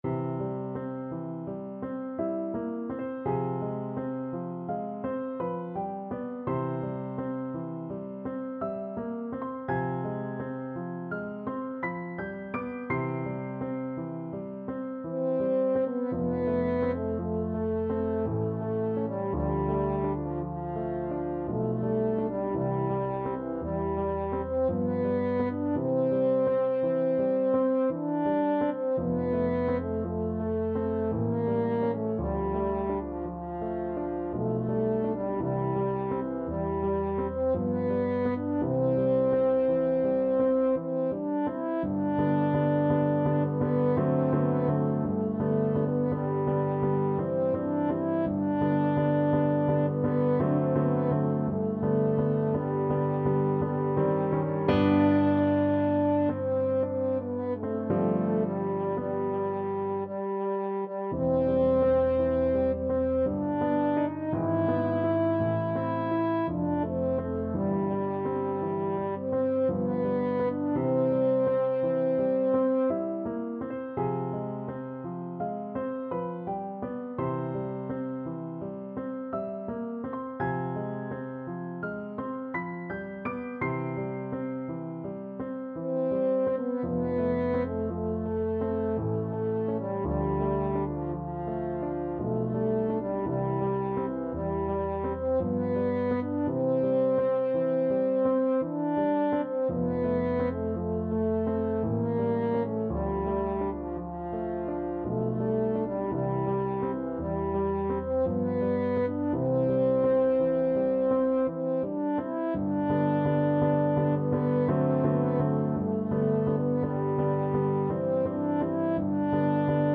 French Horn
C major (Sounding Pitch) G major (French Horn in F) (View more C major Music for French Horn )
~ = 56 Ziemlich langsam
3/4 (View more 3/4 Music)
Classical (View more Classical French Horn Music)